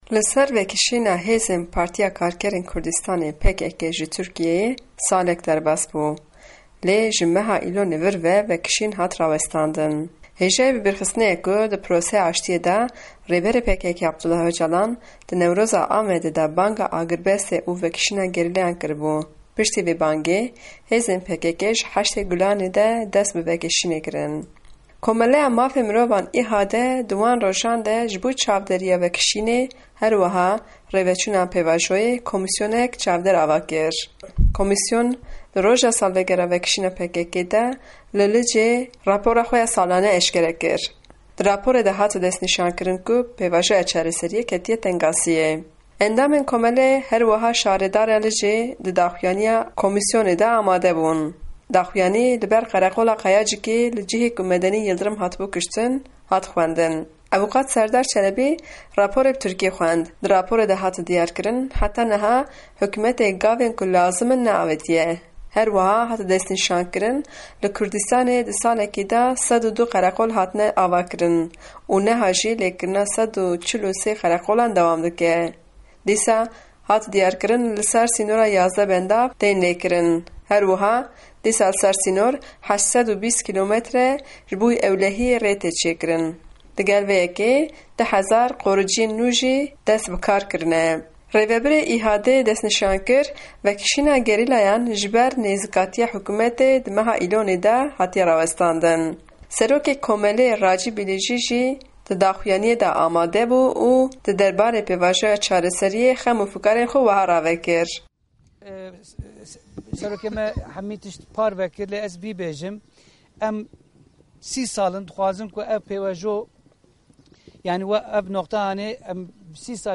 Raporta Diyarbekirê